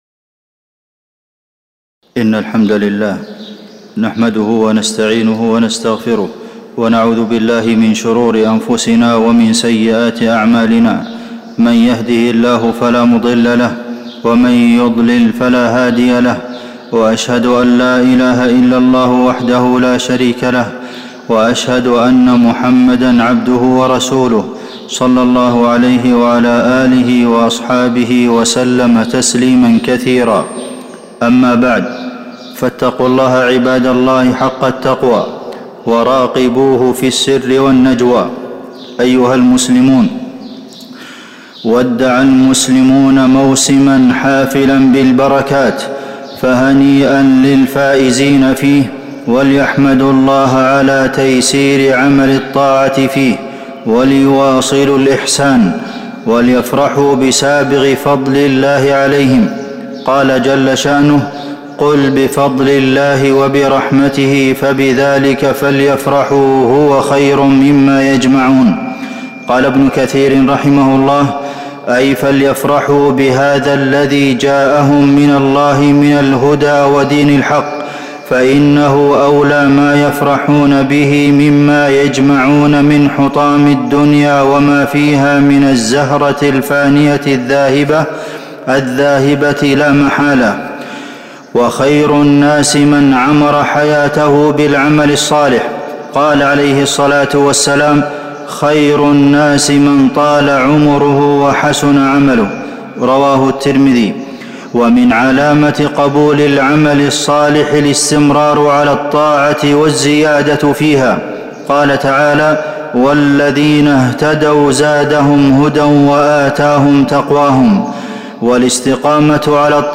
تاريخ النشر ١ شوال ١٤٤٤ هـ المكان: المسجد النبوي الشيخ: فضيلة الشيخ د. عبدالمحسن بن محمد القاسم فضيلة الشيخ د. عبدالمحسن بن محمد القاسم ختام الشهر